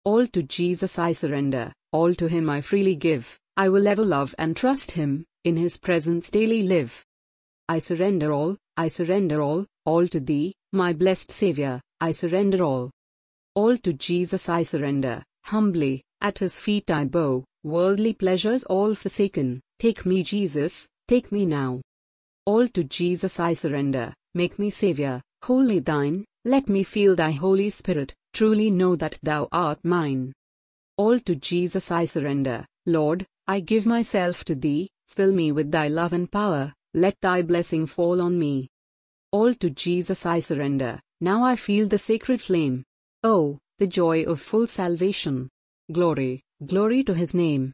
Audio Version of this article
Thanks to Amazon Polly